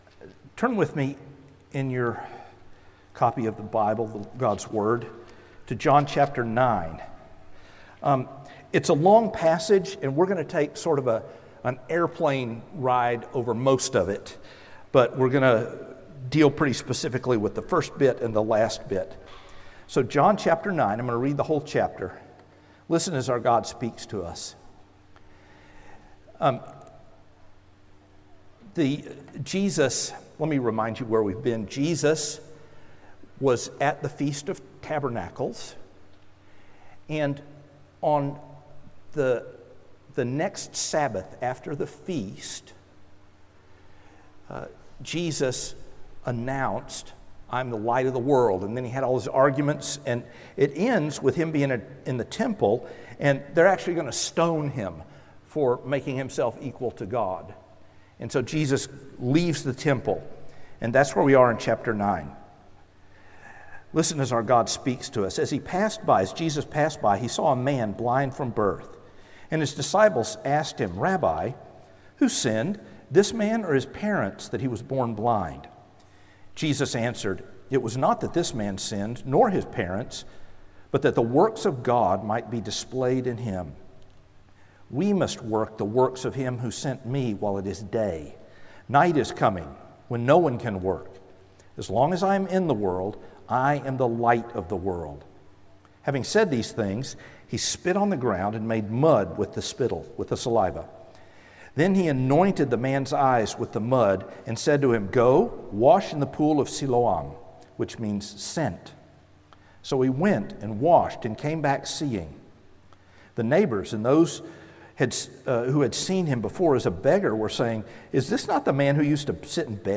Sermons – Trinity Presbyterian Church